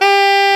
SAX A.FF G0B.wav